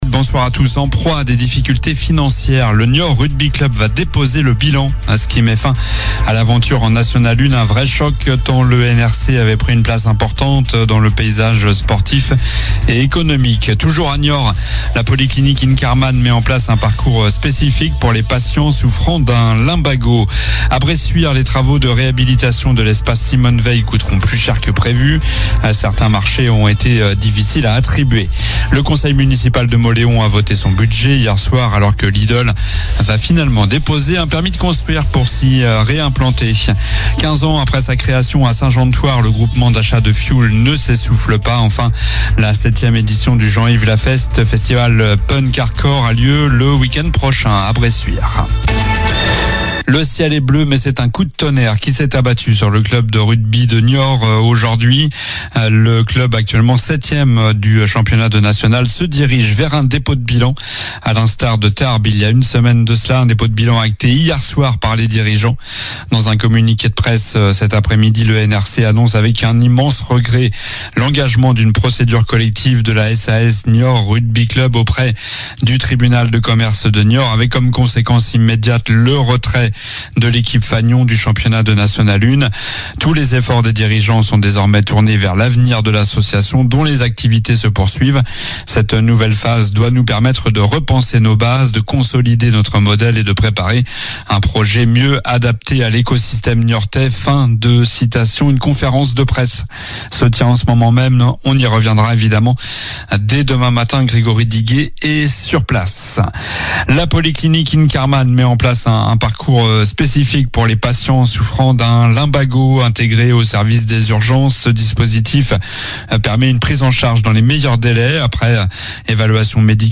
Journal du mardi 03 mars (soir)